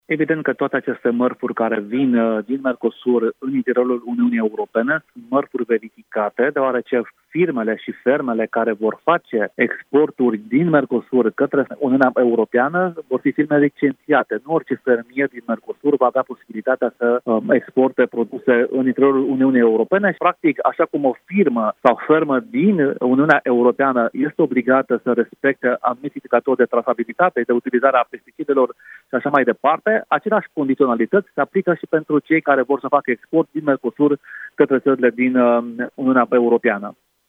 Toate firmele care vor aduce din Mercosur produse în Uniunea Europeană – inclusiv în România – trebuie să fie licențiate, garantează oficialii europeni. Este exclus ca în țările Uniunii să ajungă produse de proastă calitate, mai ales neverificate, a explicat pentru Europa FM europarlamentarul PNL Daniel Buda.